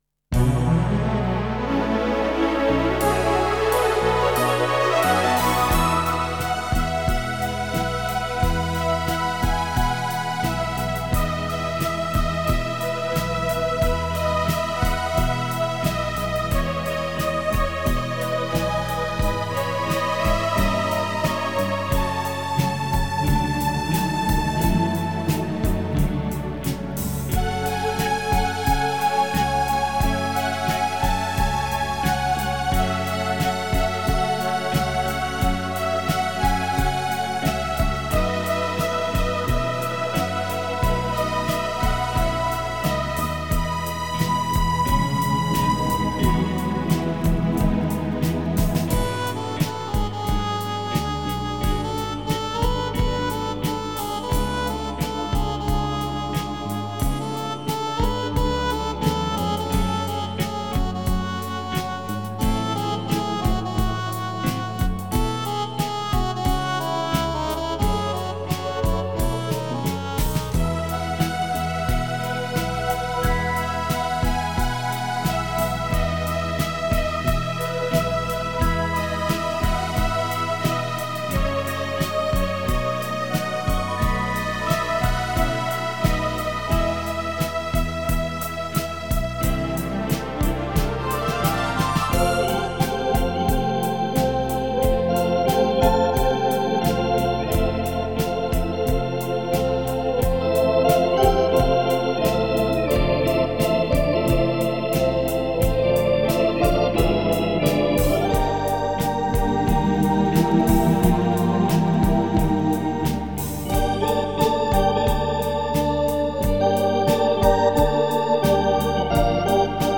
Жанр: Instrumental, Tribut